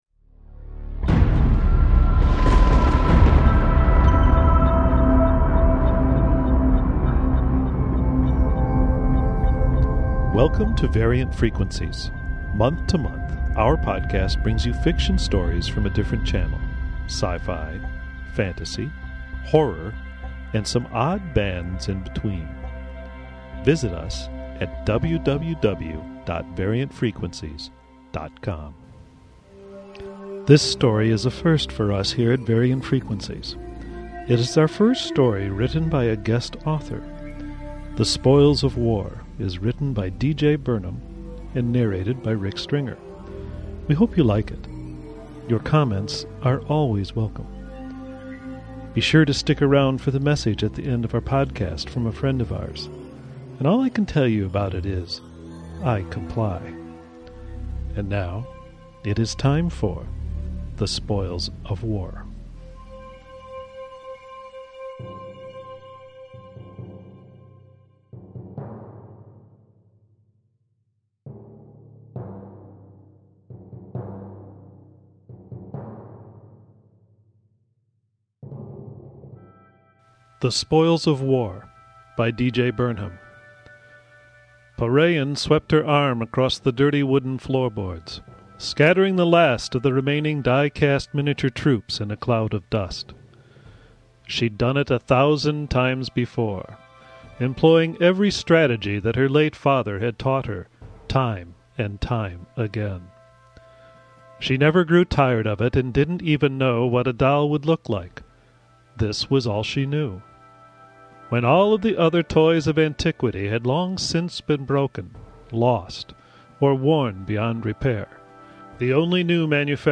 Audio Fiction